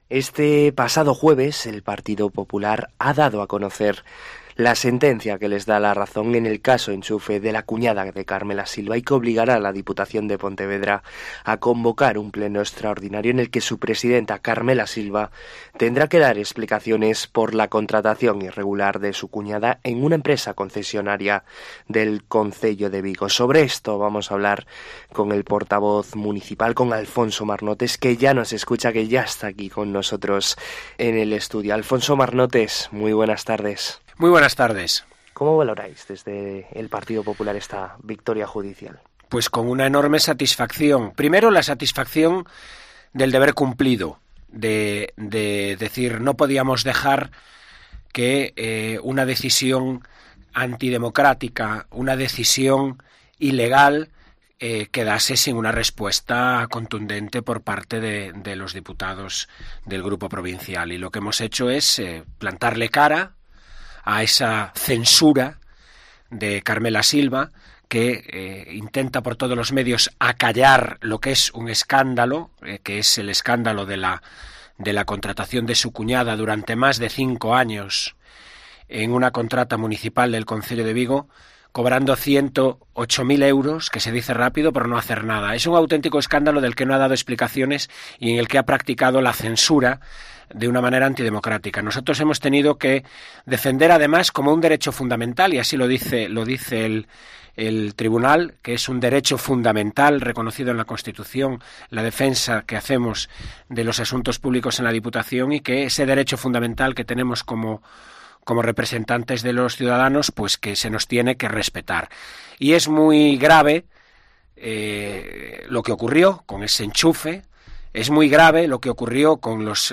En COPE Vigo hablamos de actualidad municipal con el portavoz de los populares de Vigo, Alfonso Marnotes